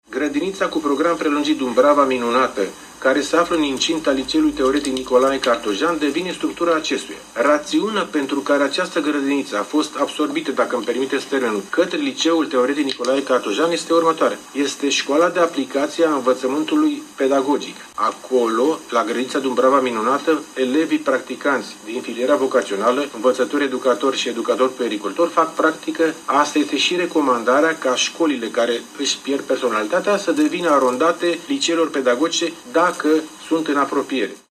Prefectul județului Giurgiu, Ion Ghimpețeanu: „Asta este și recomandarea – ca școlile care își pierd personalitatea să devină arondate liceelor pedagogice, dacă sunt în apropiere”